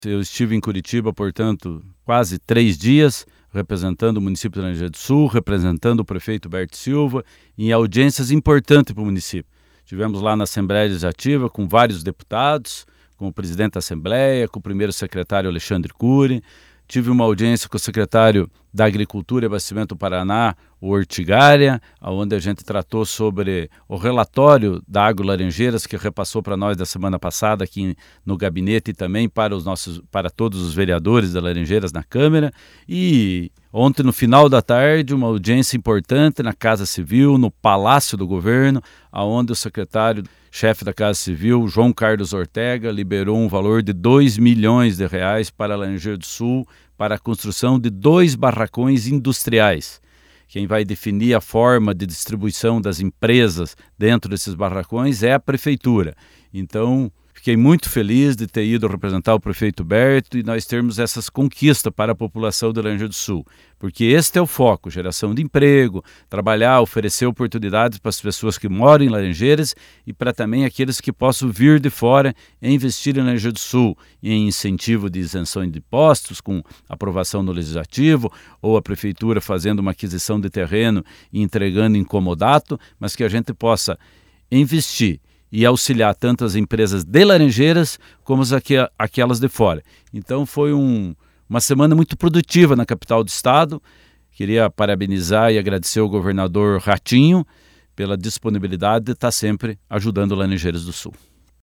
O vice-prefeito Valdemir Scarpari, faz um relato dos assuntos que foram tratados na capital do estado.